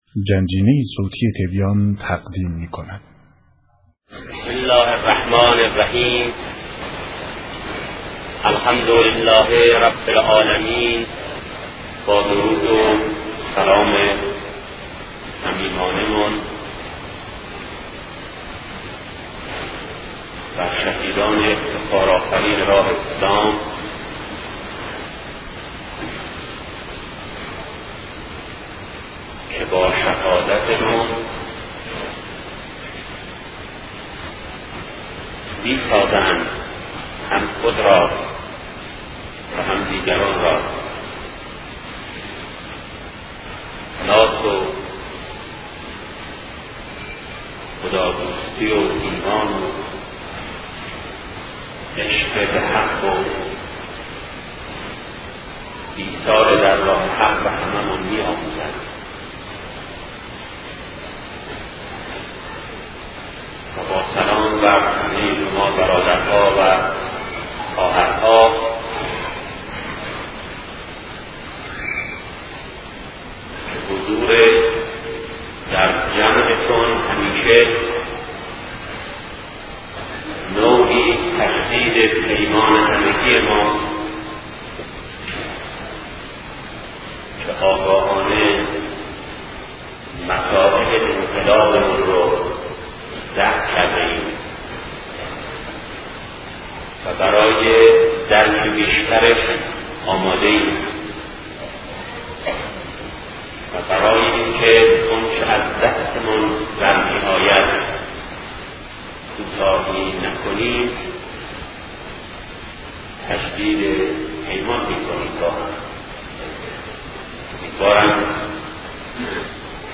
صوت سخنرانی شهید بهشتی- آیا تحزب موجب تفرقه در جامعه میشود؟-بخش‌اول